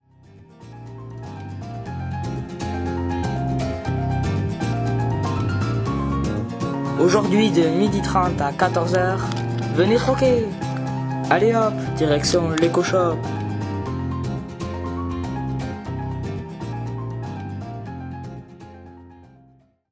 Jingle pour les jours d'ouverture: